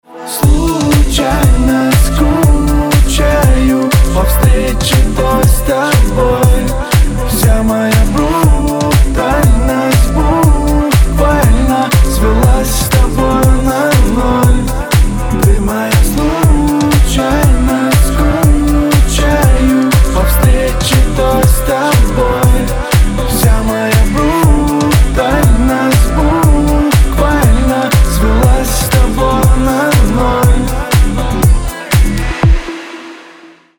поп
dance
клубнячок